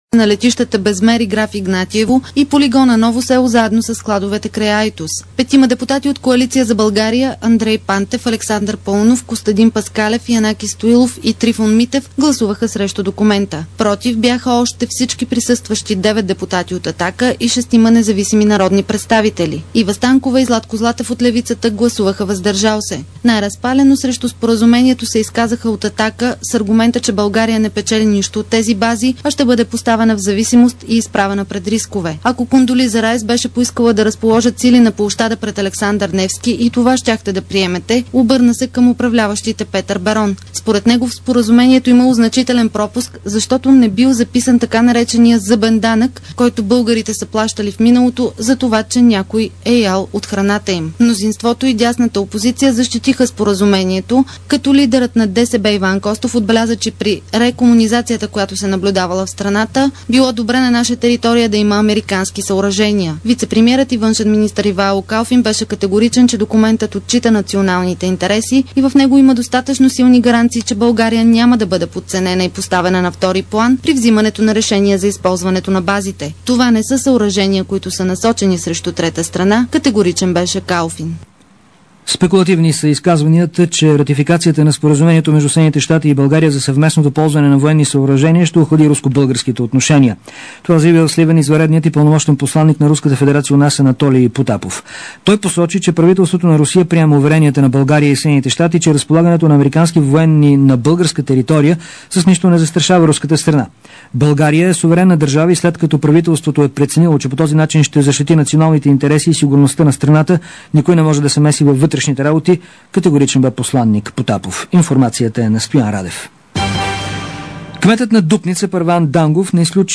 DarikNews audio: Обзорна информационна емисия – 27.05.2006